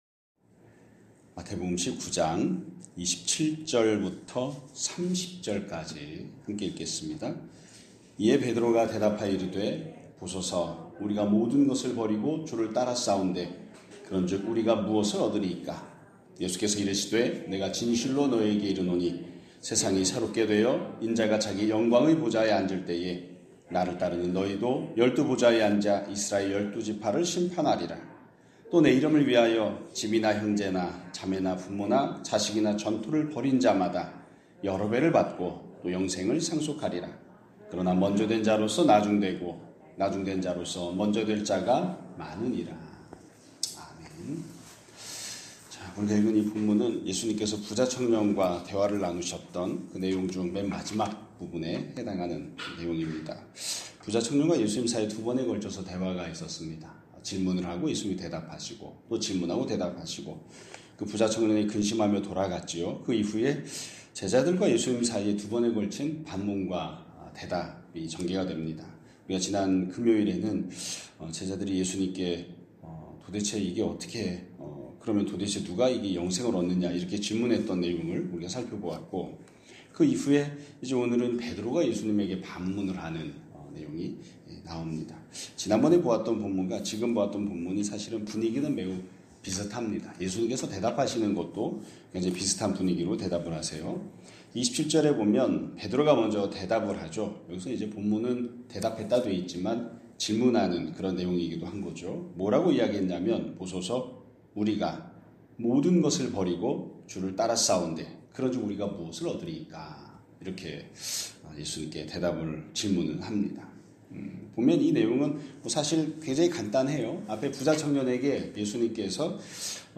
2026년 1월 12일 (월요일) <아침예배> 설교입니다.